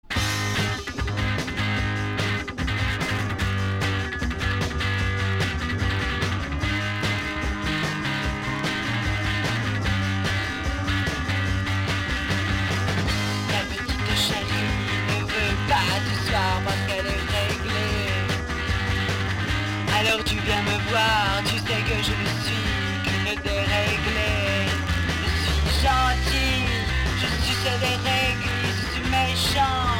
Cabaret punk Premier 45t retour à l'accueil